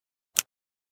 aps_holster.ogg